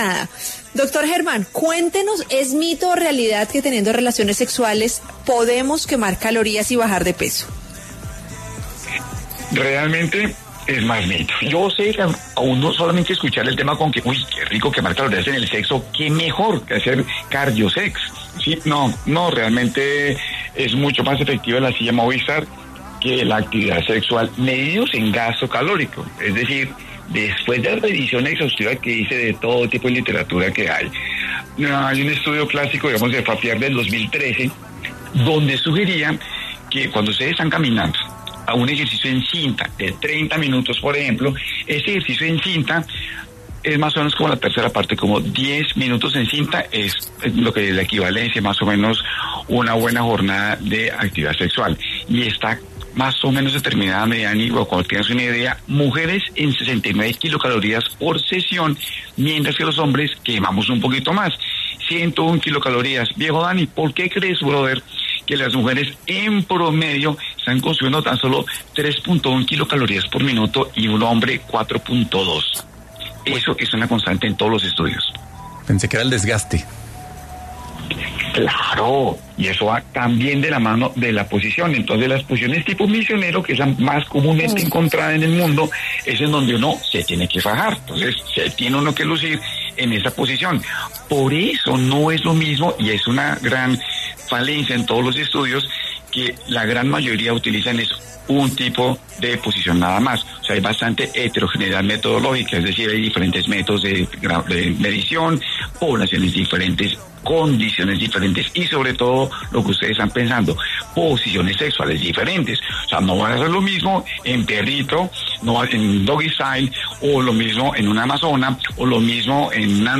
sexólogo, habló en Salud y Algo Más y comentó este mito.